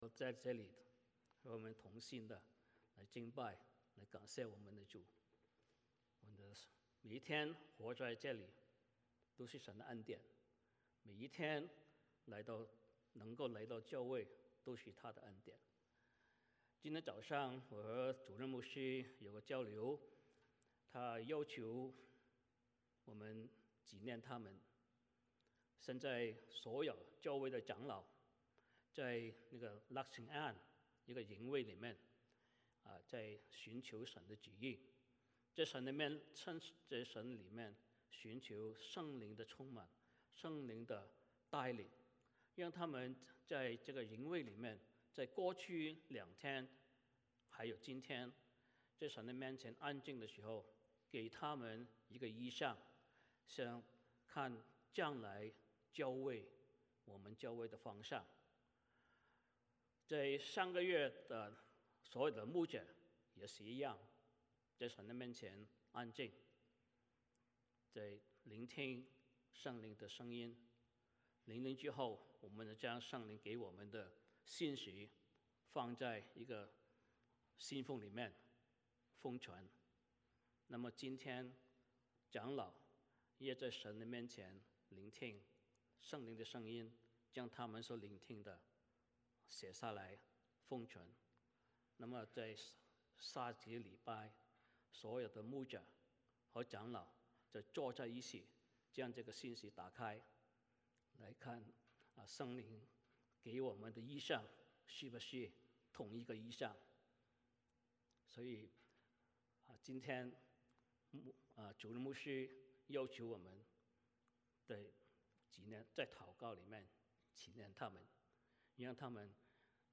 帖撒罗尼迦前书 3:1-13 Service Type: 主日崇拜 欢迎大家加入我们的敬拜。